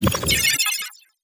Computer Calculation Notificaiton 6.wav